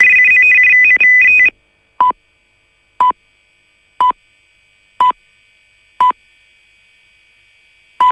Segnale orario  xx00.wav